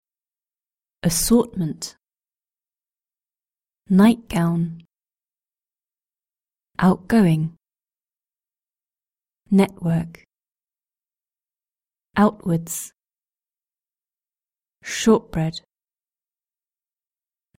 british-english-british-accent-glottal-stop-2.mp3